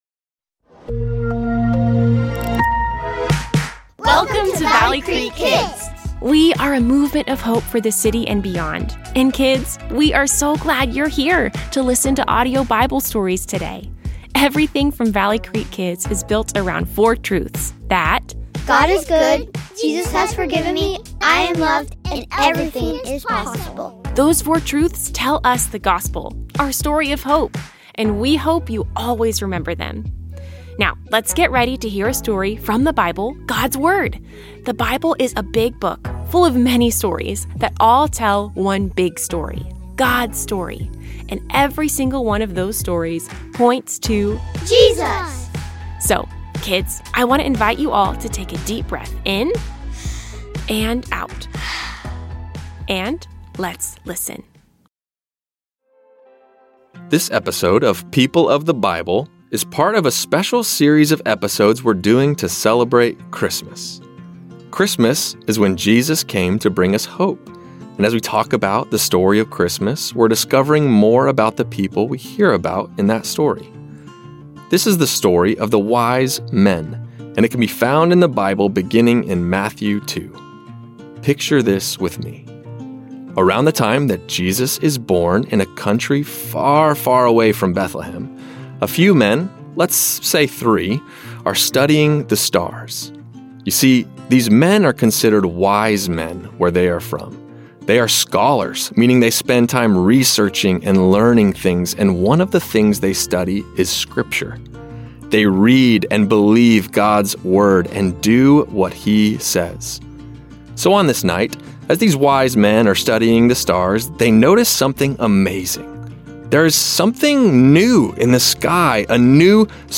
Kids Audio Bible Stories